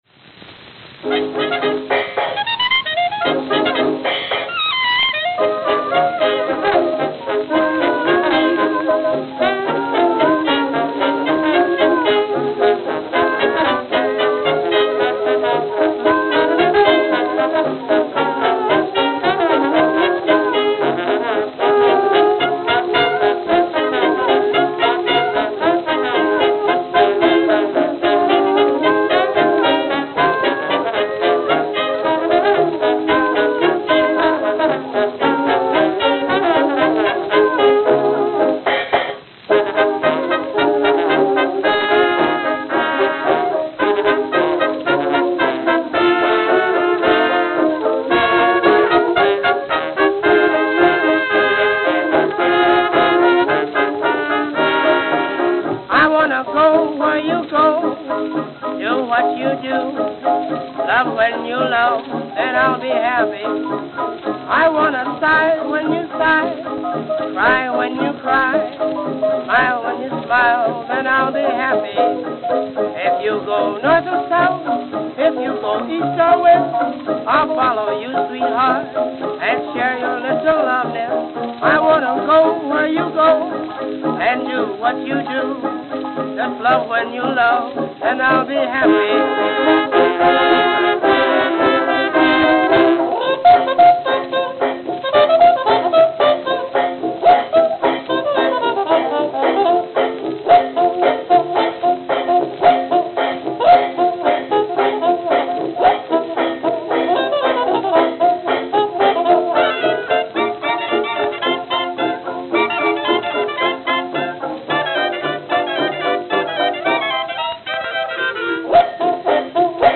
Note: In-studio bump at 0:55.